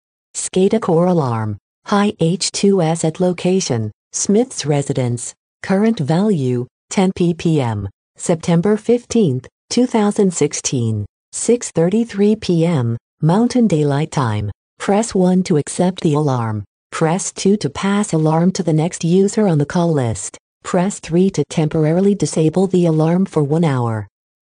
Alarm Callout system by SCADACore alerts users via Voice, SMS, and / or Email alarm notifications.
Alarm Callout Notifications
alarm-callout-high-h2s-notification.mp3